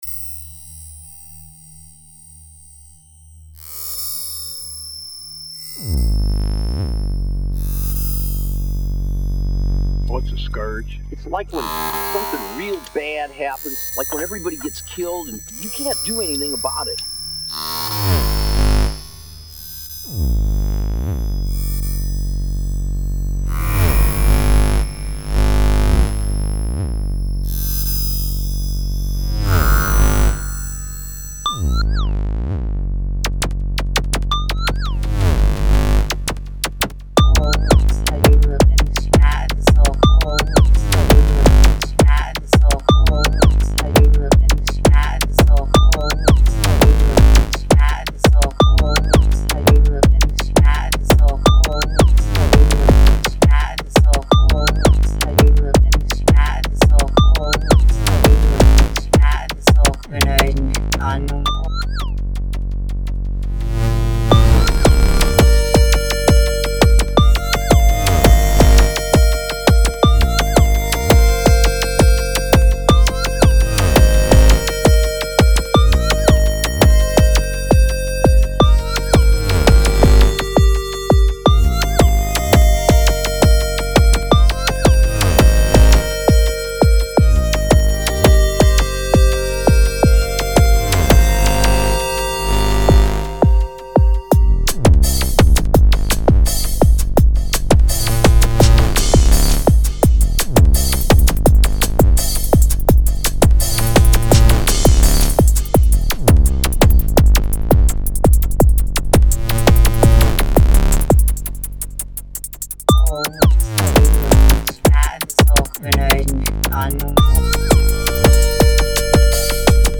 in any case, there are some previews available. so you can hear what i am up to. the most worked out piece is incidentially the one i have no title for yet. it's a melodious track in 6:8 measure with a melody sound that i was told sounds like 'psychoelectric' .... seemingly a french synth band from the 80s. the other most advanced piece is called jovian skies. both tracks as you can listen to in sneak preview are basically just basic arrangements rendered to disk. in the ultimate version there's gonna be some more. filtersweeps, small rhythmic variations and other dynamic elements i'll do live in the mix because i like to do so. other people like to automate everything down to the last little flick of a switch, but i am not going all that far. electronic music is very preconceived and stiffly regulated as it is already ... nothing wrong about throwing in some human element.
on the frontier. it features a voice sample from the movie dead man and for the rest, all sounds except the hihats and the granulizer wrenched intro are made with an fm matrix synth. this piece is still very much a draft. very simplistic in it's rhythmic section, pretty short and not yet featuring the variation in melody i want to achieve.